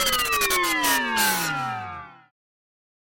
fall_death.mp3